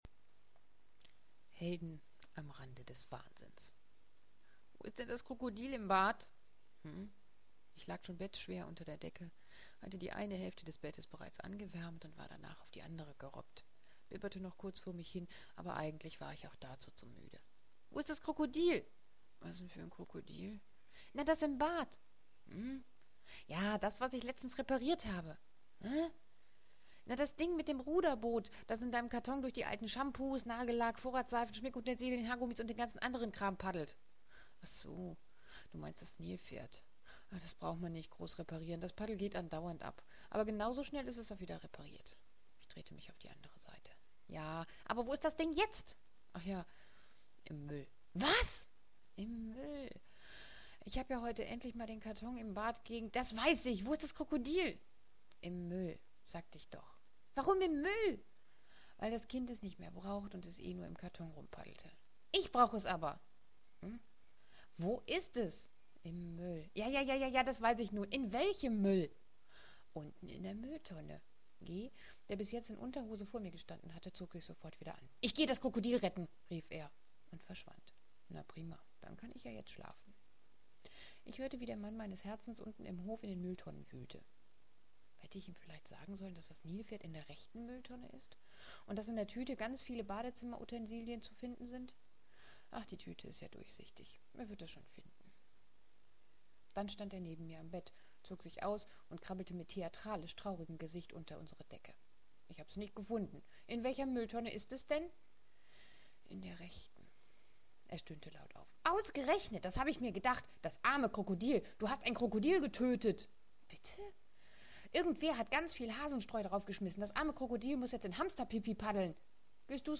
Ich bitte Verleser zu entschuldigen.